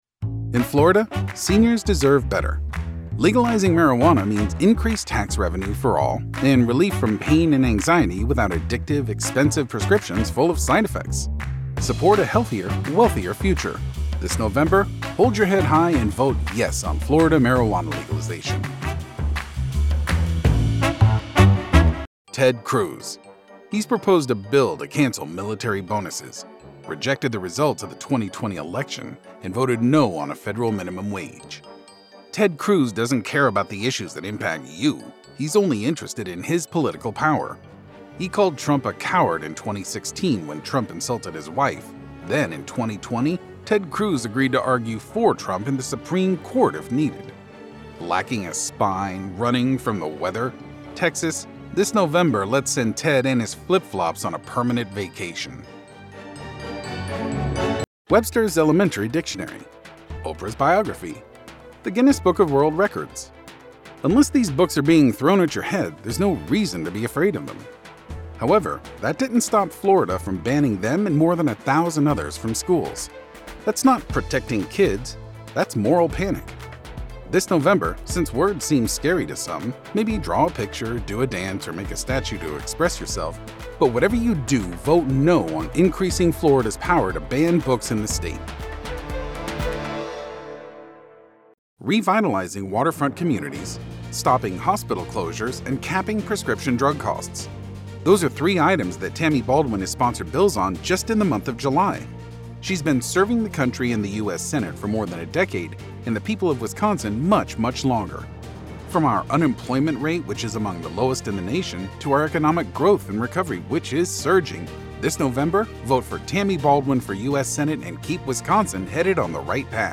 Political Demo
English - USA and Canada
Young Adult
Middle Aged